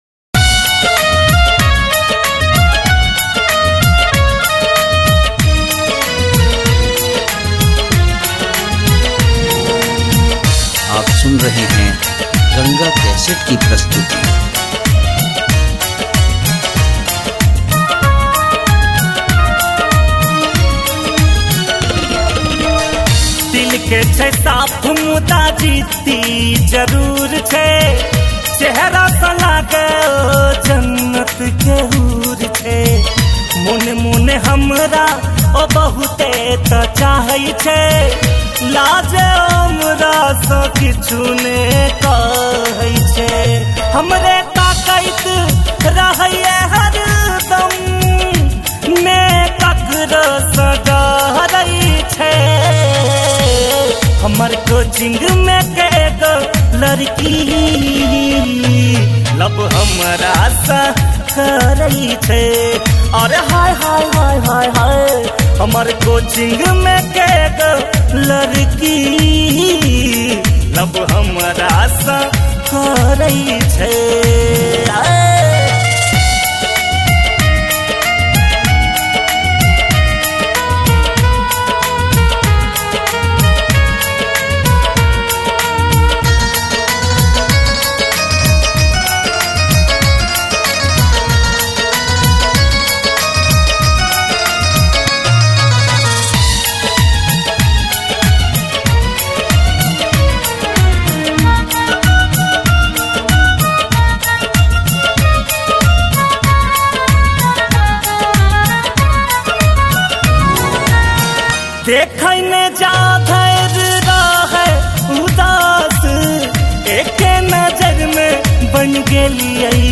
Maithili Song